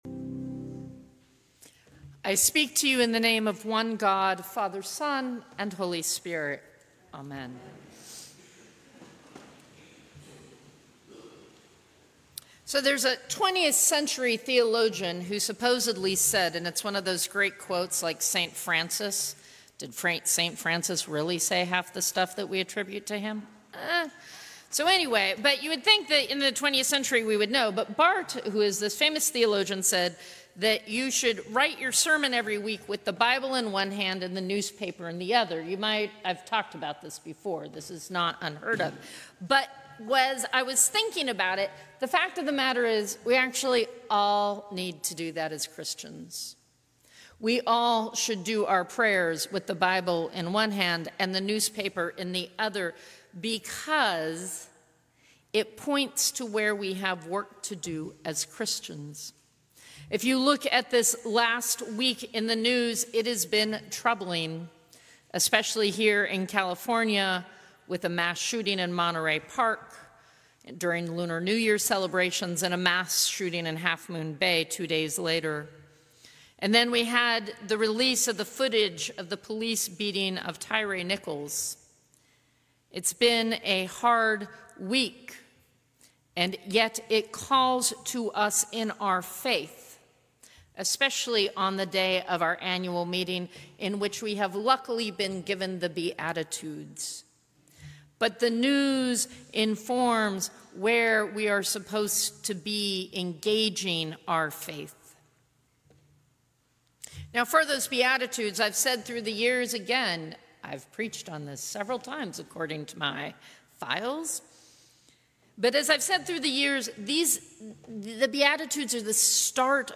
Sermons from St. Cross Episcopal Church Fourth Sunday after the Epiphany Jan 08 2024 | 00:11:18 1x 00:00 / 00:11:18 Subscribe Share Apple Podcasts Spotify Overcast RSS Feed Share Link Embed